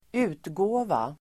Uttal: [²'u:tgå:va]